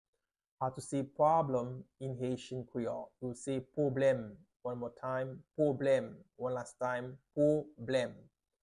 How to say "Problem" in Haitian Creole - "Pwoblèm" pronunciation by a native Haitian teacher
“Pwoblèm” Pronunciation in Haitian Creole by a native Haitian can be heard in the audio here or in the video below:
How-to-say-Problem-in-Haitian-Creole-Pwoblem-pronunciation-by-a-native-Haitian-teacher.mp3